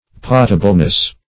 Potableness \Po"ta*ble*ness\, n. The quality of being drinkable.